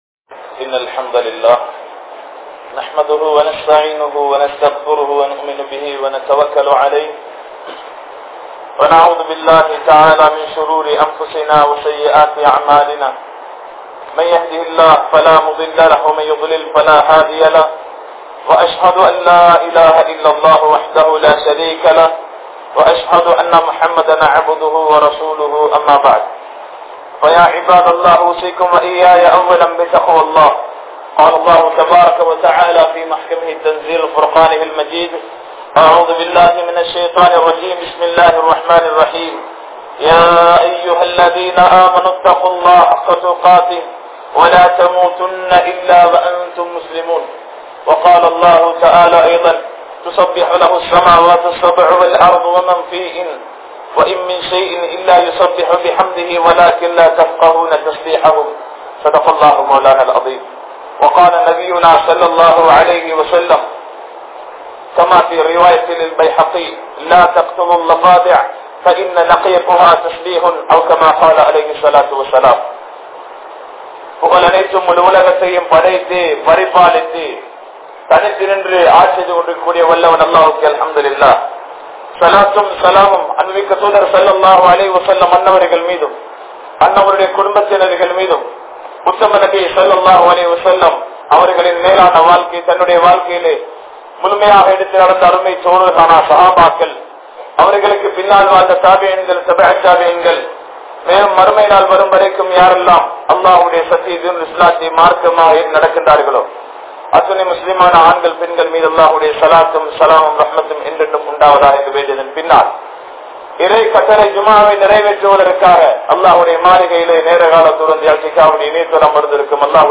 Allah`vai Maranthu Vidaatheerhal (அல்லாஹ்வை மறந்து விடாதீர்கள்) | Audio Bayans | All Ceylon Muslim Youth Community | Addalaichenai
Dambulla, Khairiya Jumua Masjidh